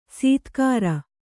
♪ sītkāra